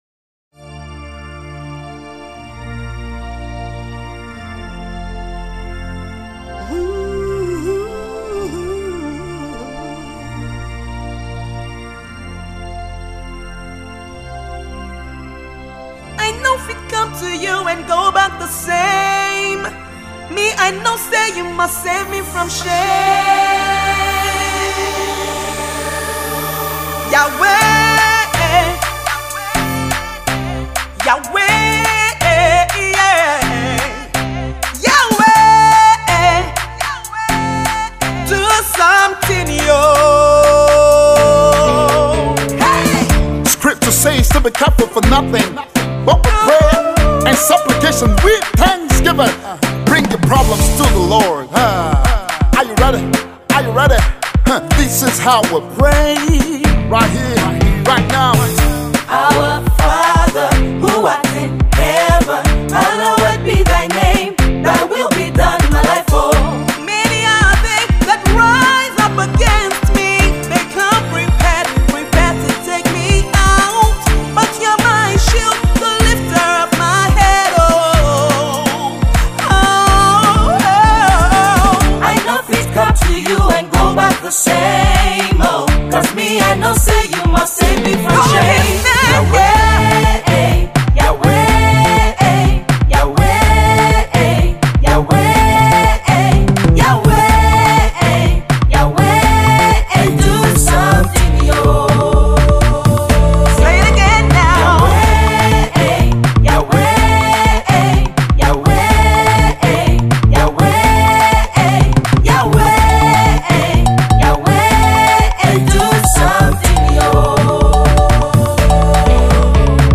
Praise/Prayer song